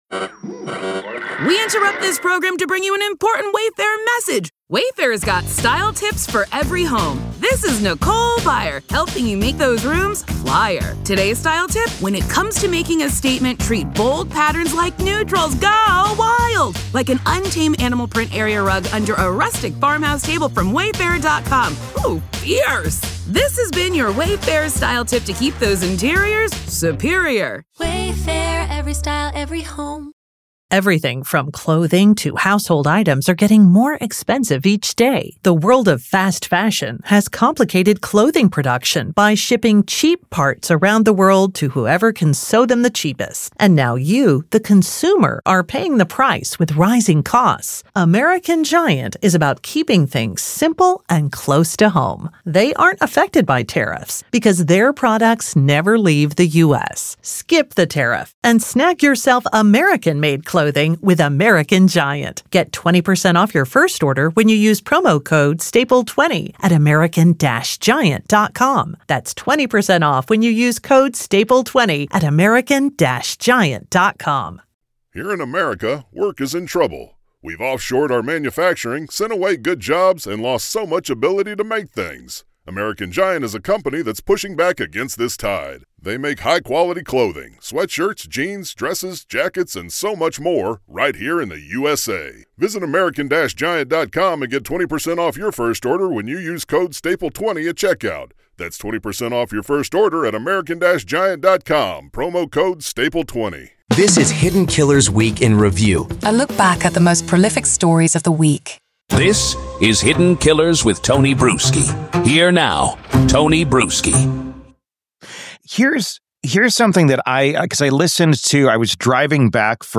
From high-profile criminal trials to in-depth examinations of ongoing investigations, this podcast takes listeners on a fascinating journey through the world of true crime and current events. Each episode navigates through multiple stories, illuminating their details with factual reporting, expert commentary, and engaging conversation.